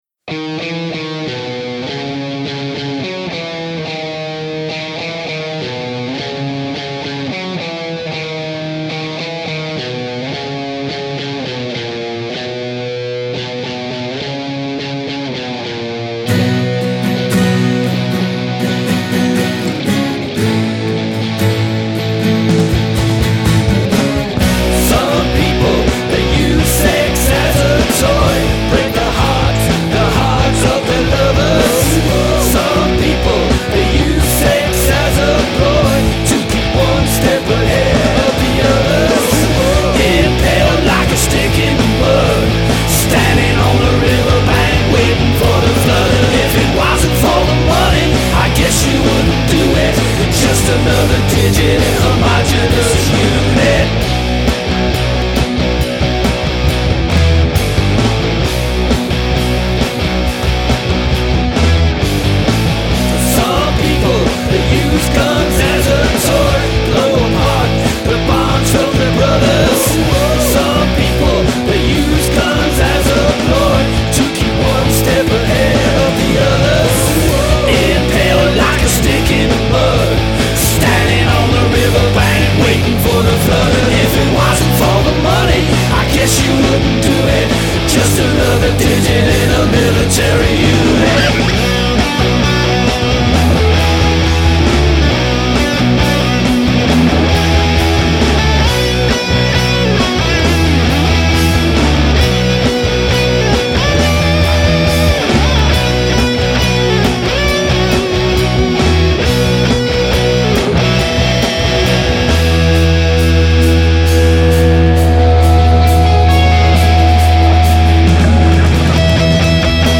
The guitar intro is from my guide guitar track.